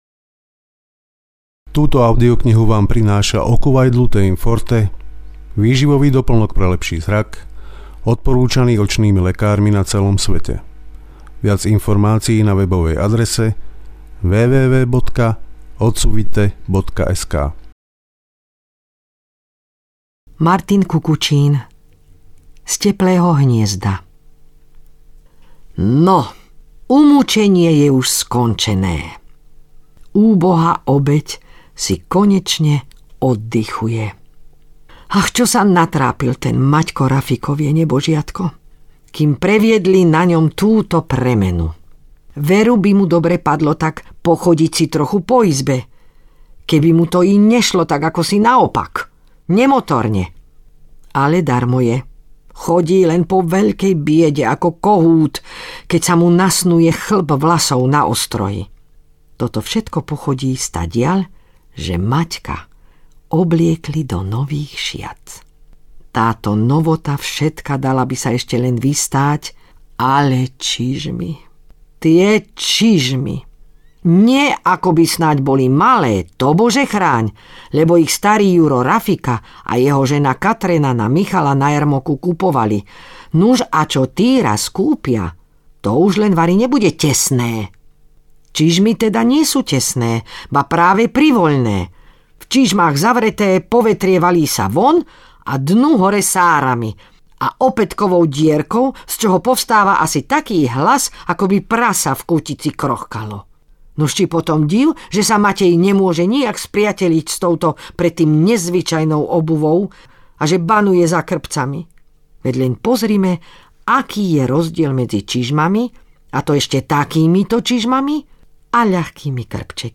Martin Kukučín - Z teplého hniezda (hovorené slovo).mp3